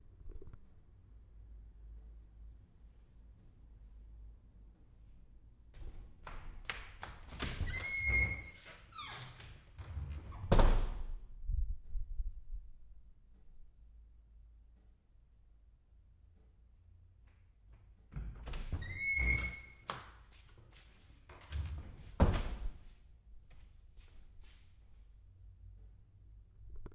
马车 " 马车 1人上车
描述：用于电影的24bit / 48khz现场录制。 18世纪的马车
标签： 关闭 开放 脚步声
声道立体声